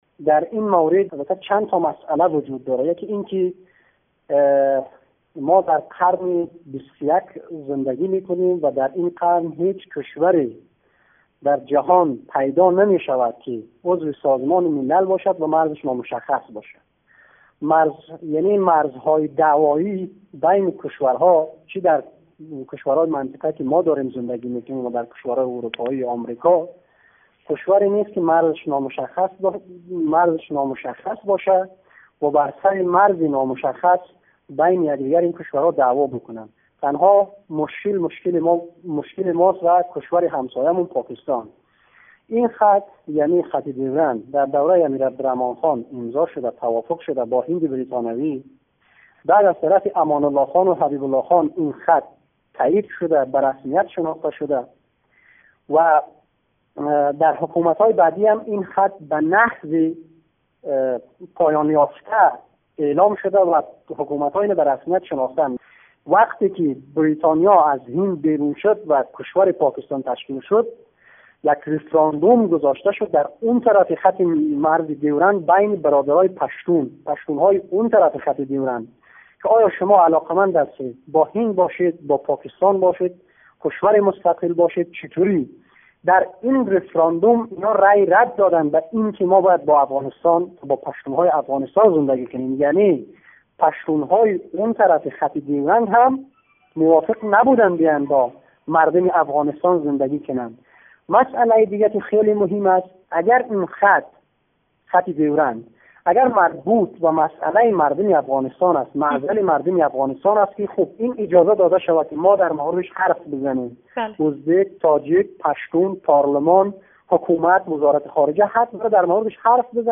کارشناس سیاسی افغان
گفت و گو